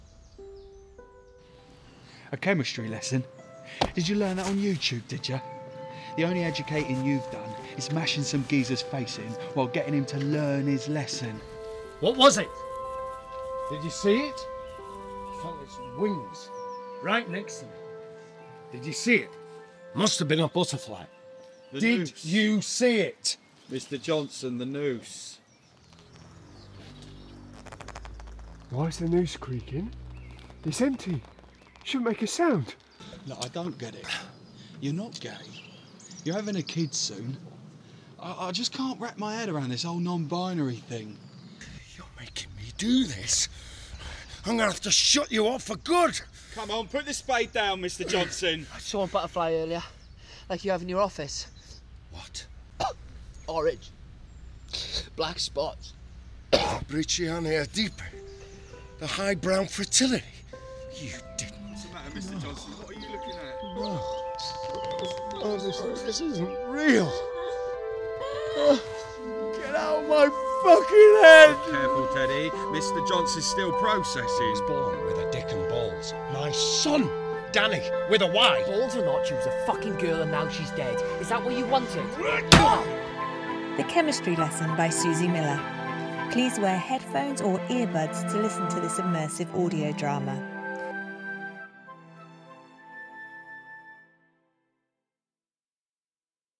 The Chemistry Lesson The Chemistry Lesson (3D audio: Listen with Headphones/Earbuds) - Audioteria